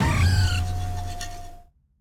Archivo:Grito de Melenaleteo.ogg
Categoría:Gritos de Pokémon de la novena generación Categoría:Melenaleteo